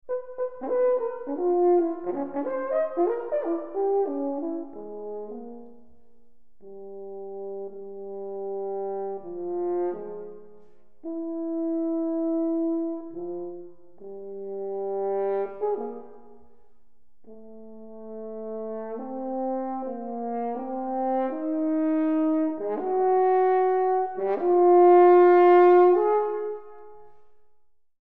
Horn
Iwaki Auditorium, ABC Southbank, Melbourne